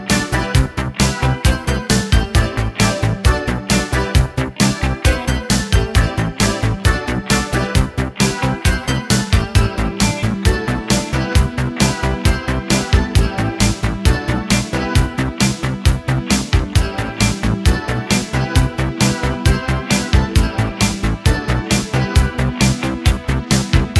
no Backing Vocals Pop (1980s) 3:51 Buy £1.50